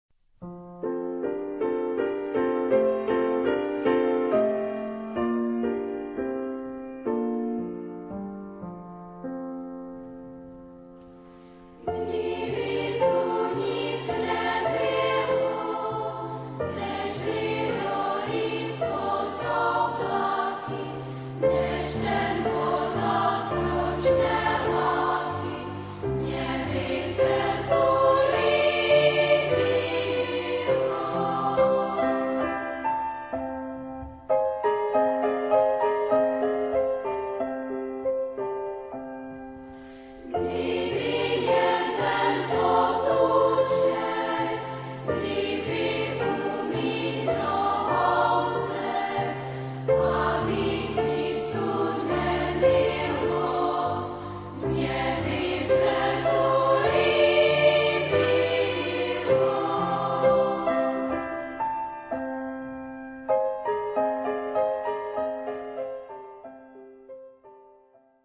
Type of Choir: SSA  (3 children voices )
Instruments: Piano (1)
Tonality: F major